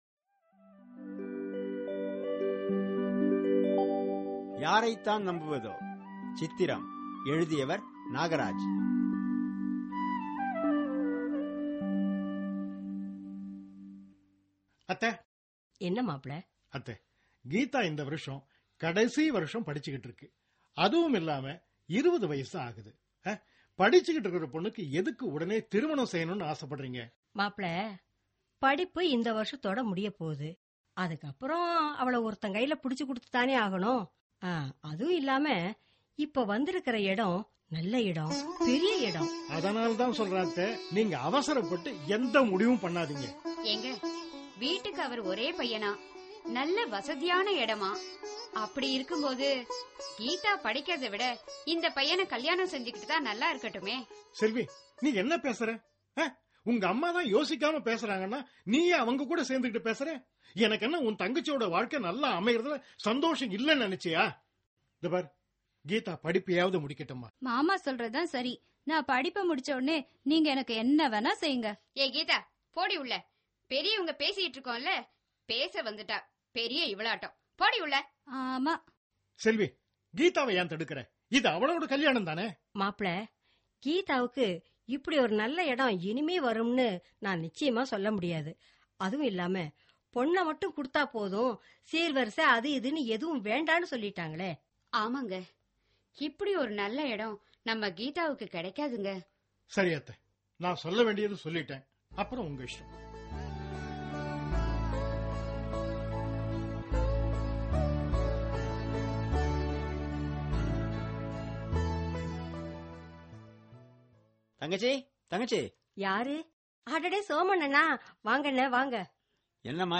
Social Drama